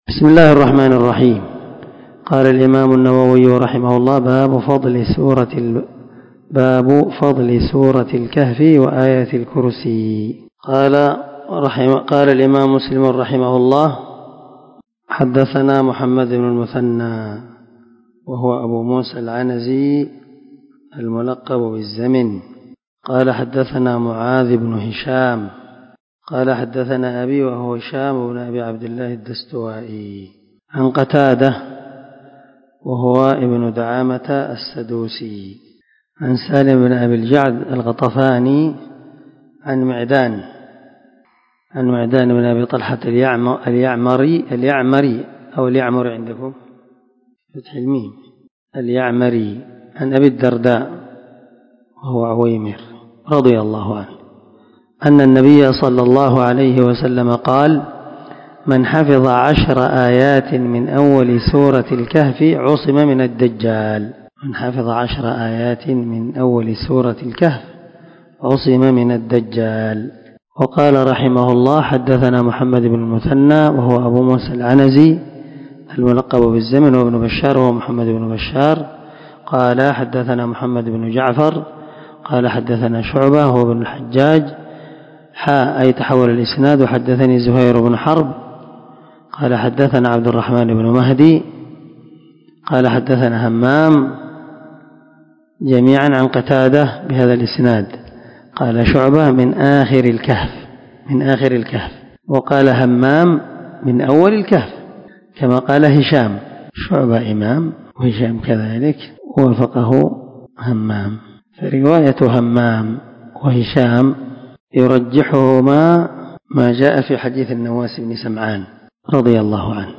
سلسلة_الدروس_العلمية